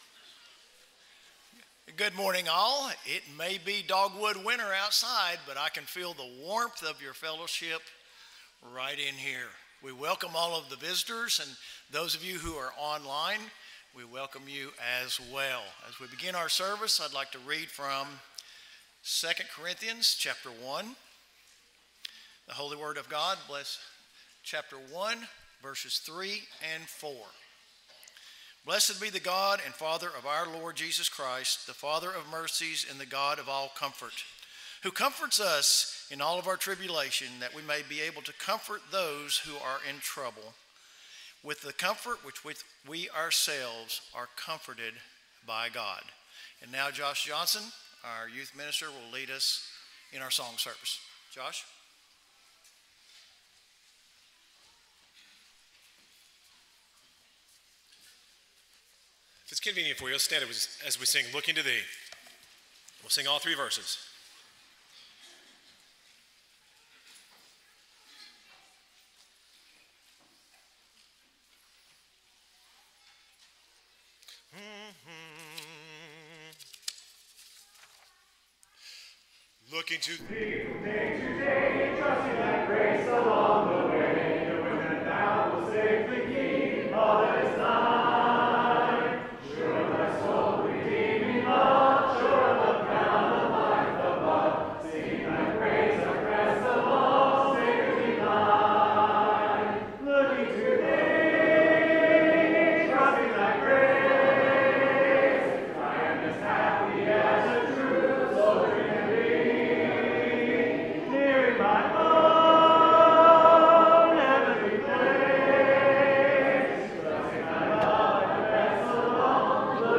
Acts 20:27, English Standard Version Series: Sunday AM Service